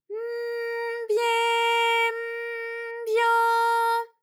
ALYS-DB-001-JPN - First Japanese UTAU vocal library of ALYS.
by_m_bye_m_byo.wav